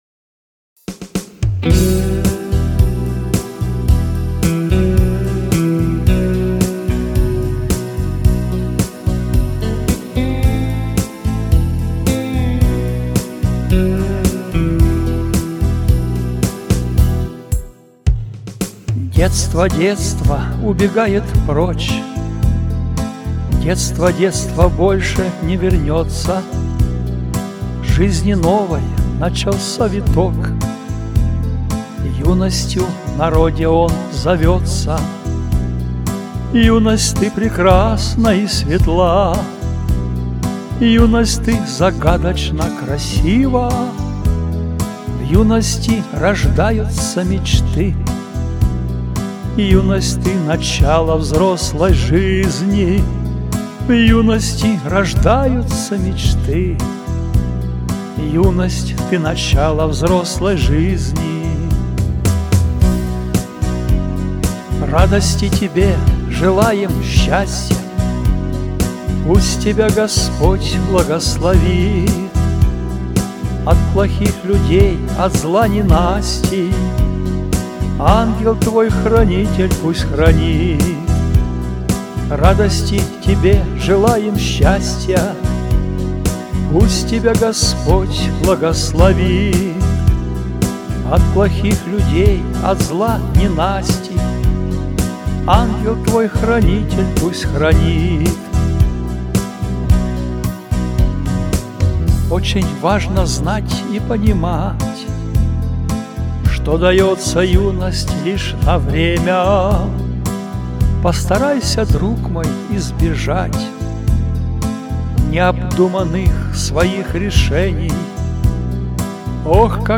Христианские песни